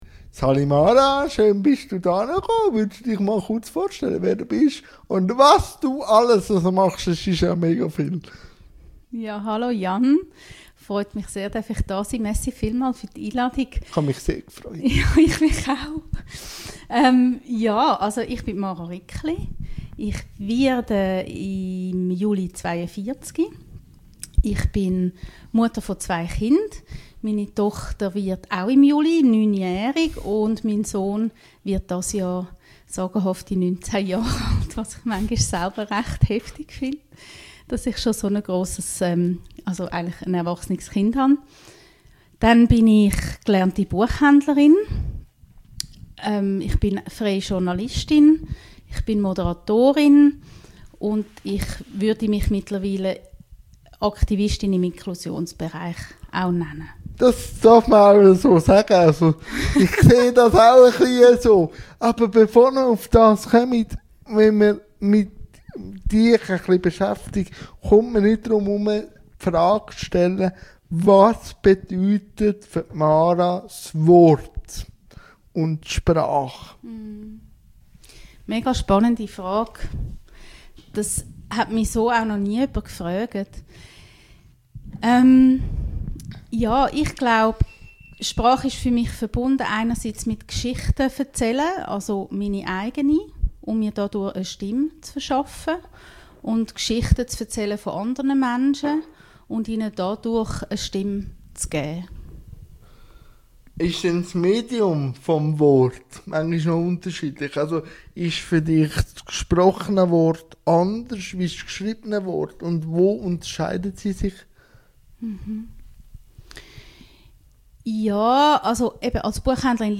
INTERVIEW-THEMEN